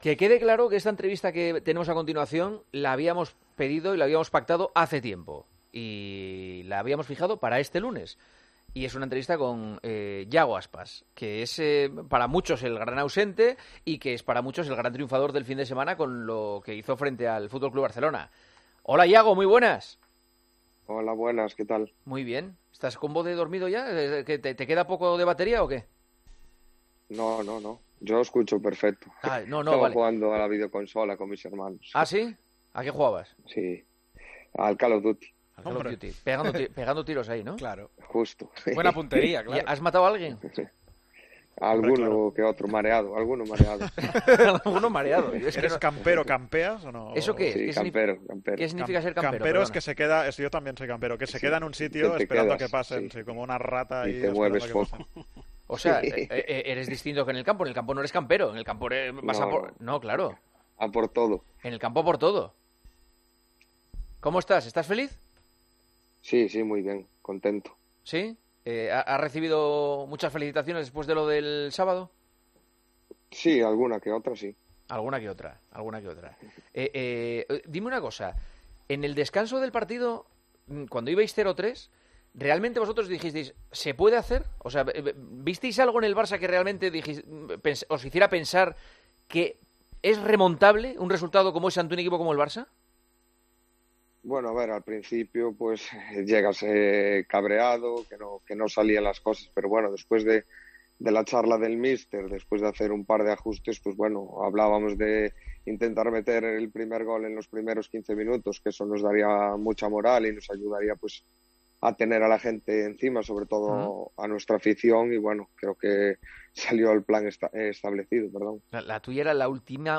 AUDIO - ENTREVISTA A IAGO ASPAS, EN EL PARTIDAZO DE COPE